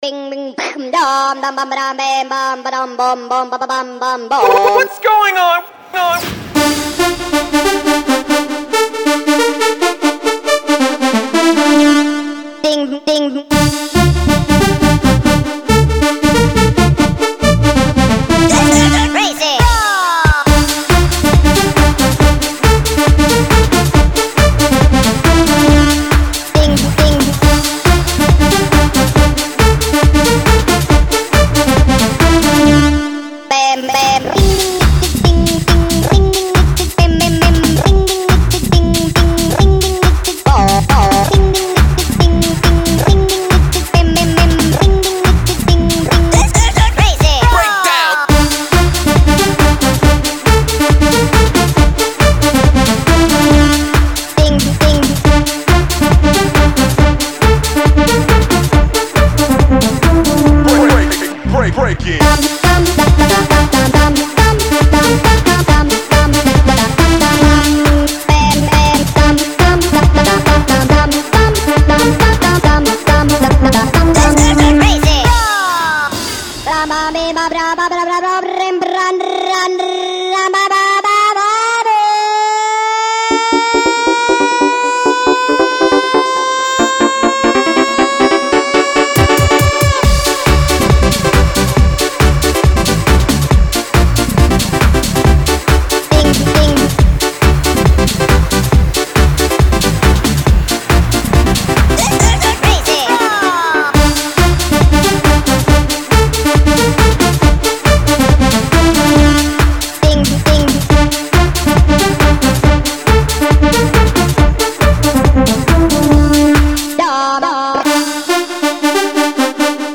BPM138
Audio QualityLine Out